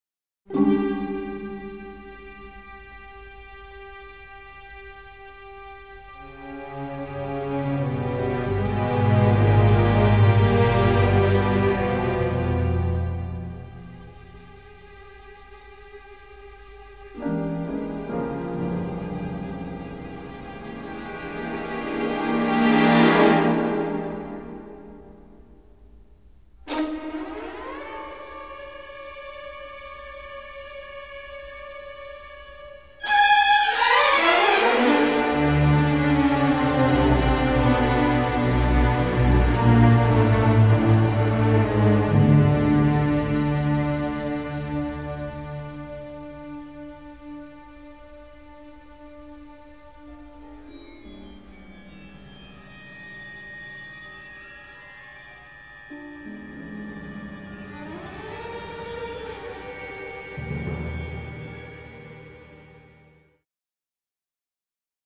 dark thriller score
original stereo session mixes